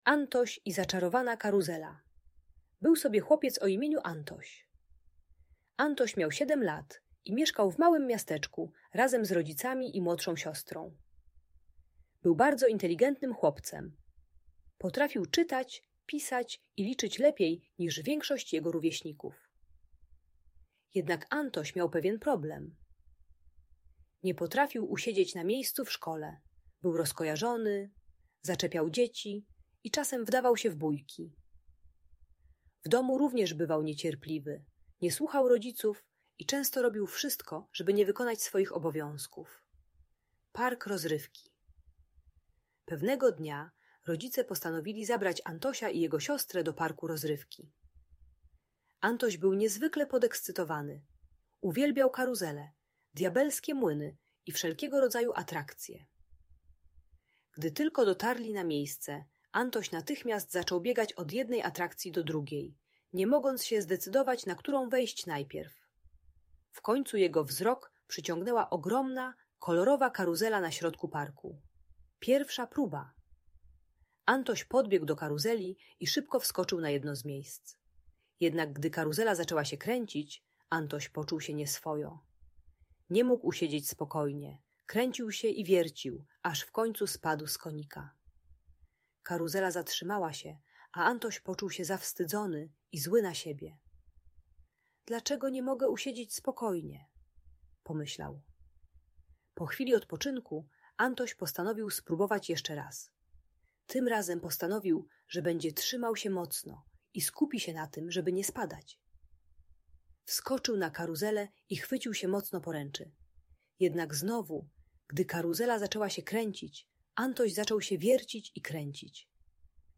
Antoś i Zaczarowana Karuzela - magiczna story dla dzieci - Audiobajka